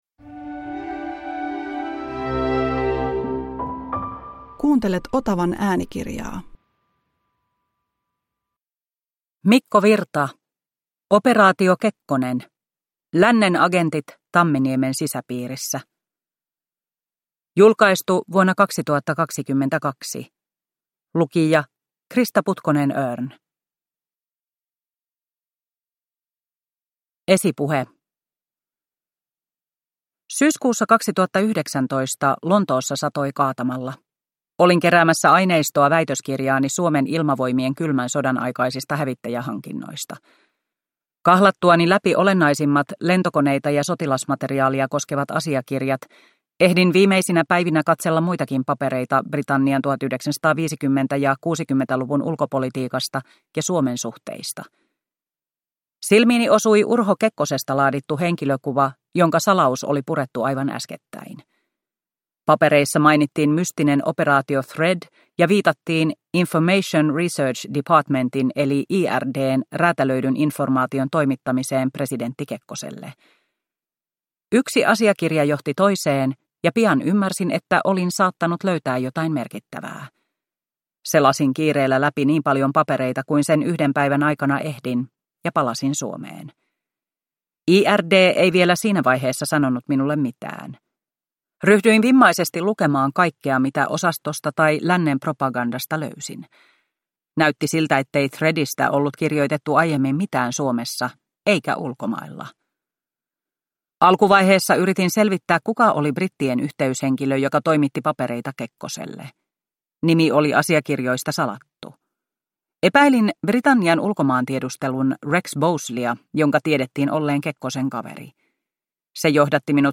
Operaatio Kekkonen – Ljudbok – Laddas ner